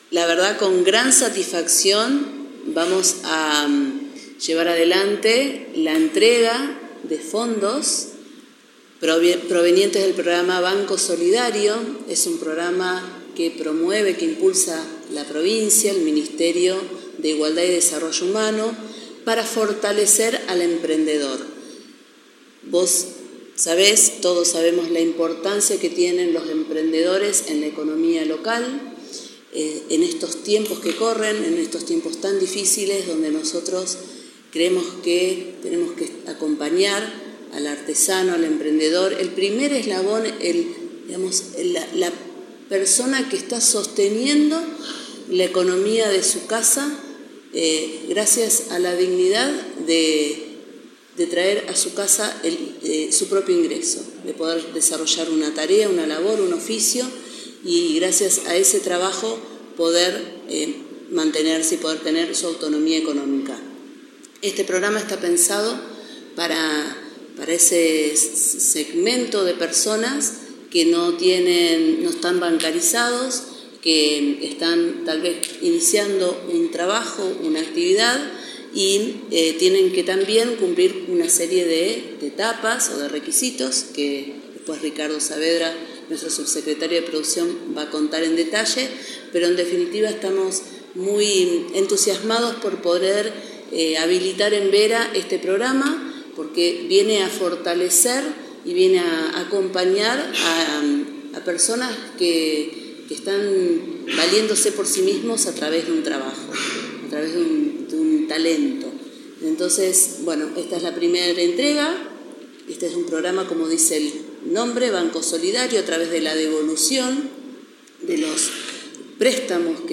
Paula Mitre – Intendente de la Ciudad de Vera